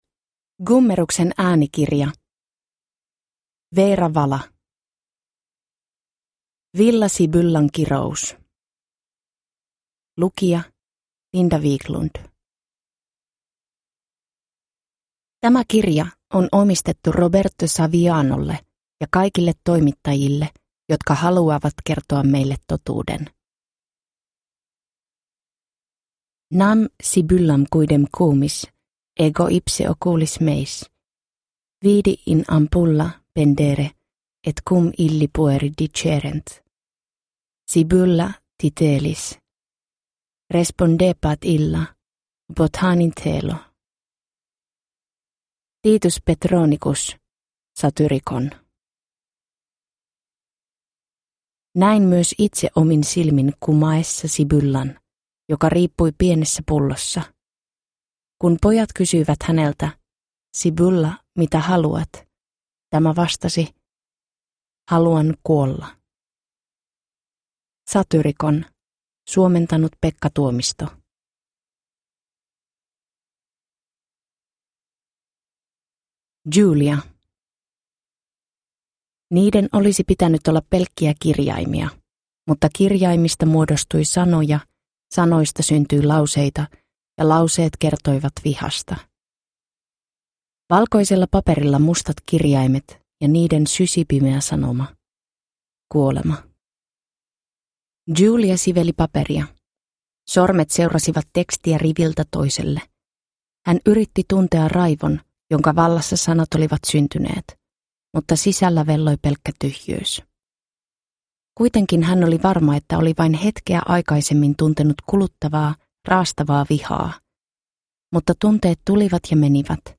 Villa Sibyllan kirous – Ljudbok